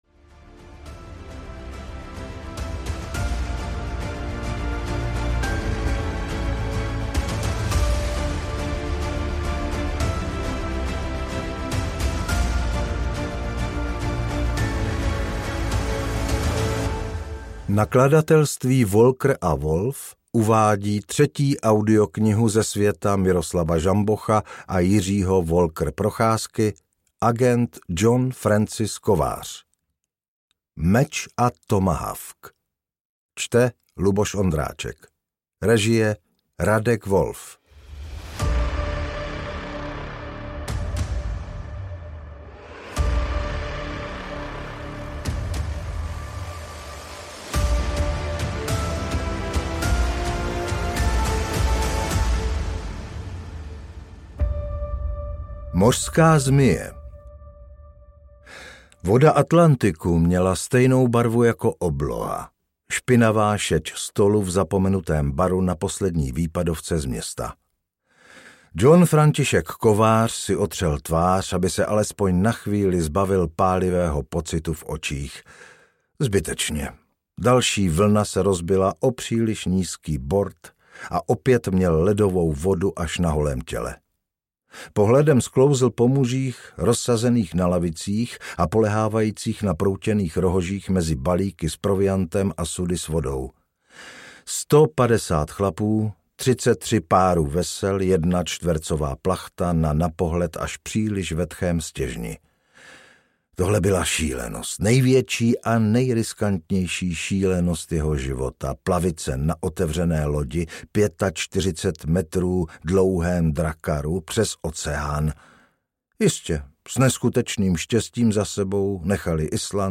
Meč a tomahawk audiokniha
Ukázka z knihy
mec-a-tomahawk-audiokniha